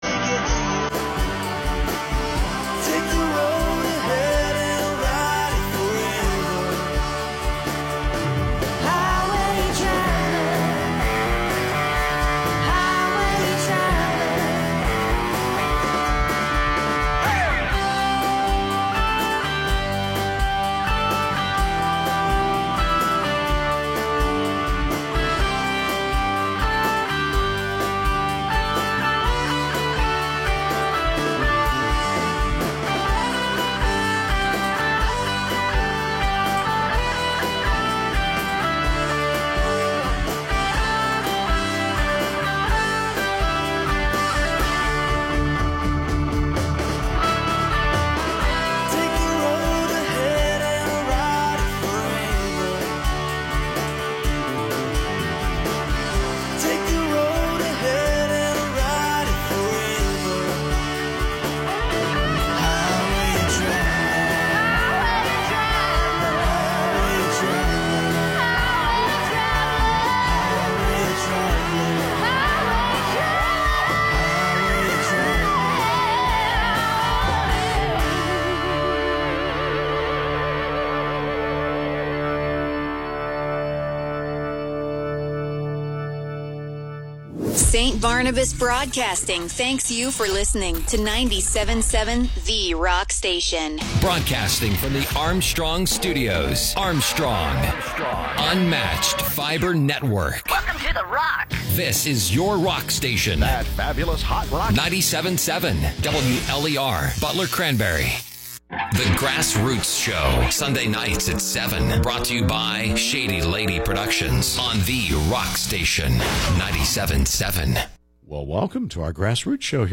We welcome to the studio today Butler area band MajiNatt.